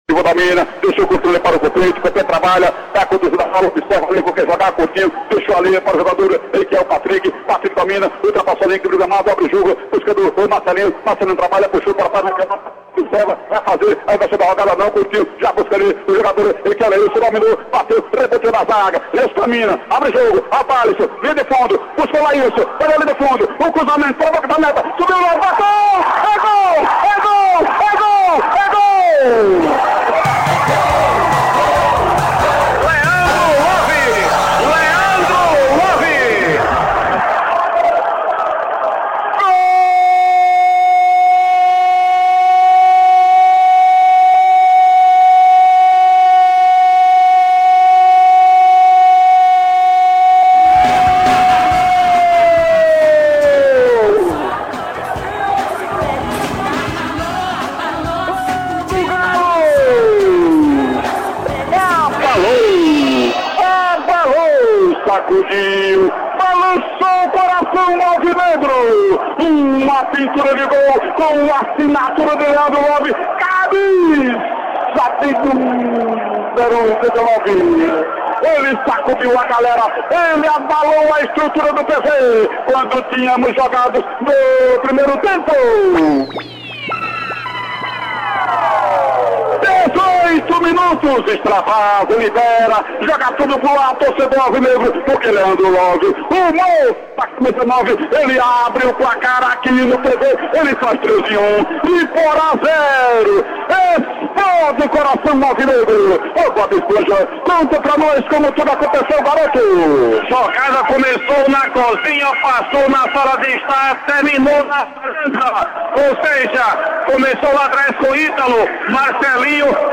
A emoção do gol na voz